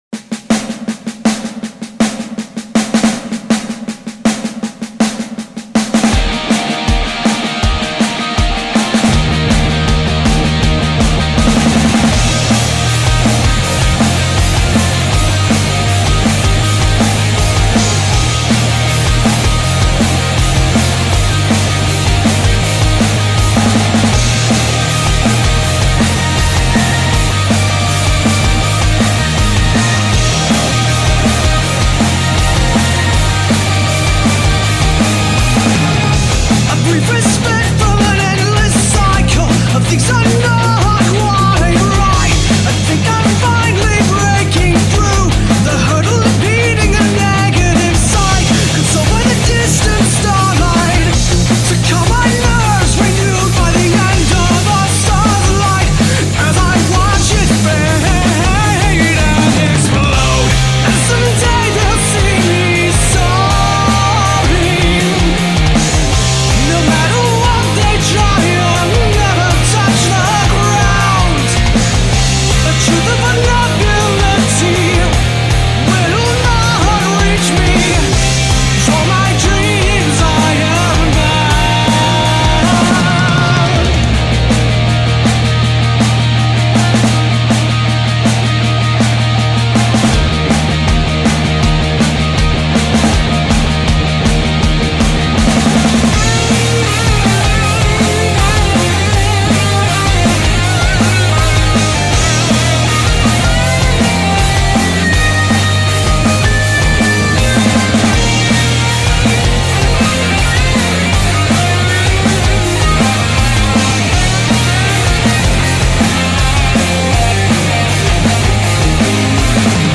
You are hearing a single vocal track with post-processing.
genre:rock